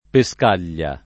Pescaglia [ pe S k # l’l’a ]